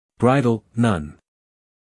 英音/ ˈbraɪd(ə)l / 美音/ ˈbraɪd(ə)l /